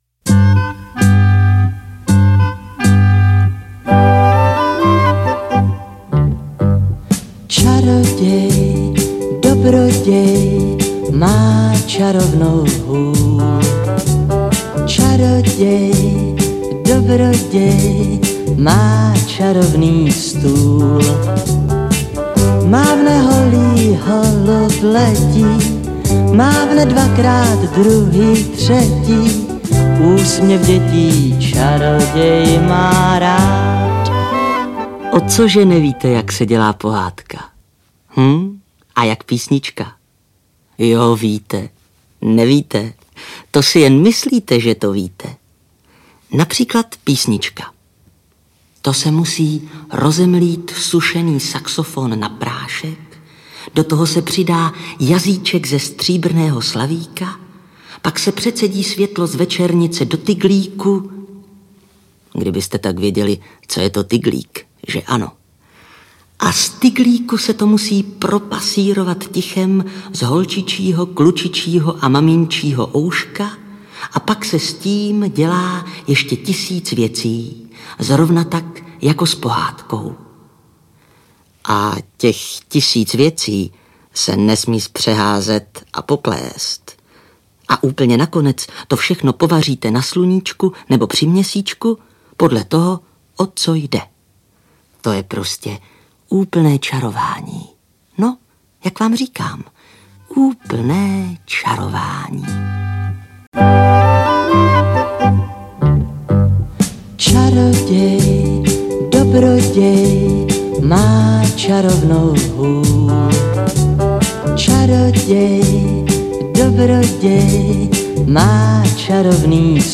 • AudioKniha ke stažení Vašek vypravuje pohádky Františka Nepila (komplet)
Interpret:  Václav Neckář
Václav Neckář není jen legendární zpěvák, ale také skvělý vypravěč.
Ty v sedmdesátých letech kouzelně načetl právě Václav Neckář a byly doplněny také o krátké velmi známé písničky.